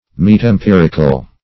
Metempiric \Met`em*pir"ic\, Metempirical \Met`em*pir"ic*al\, a.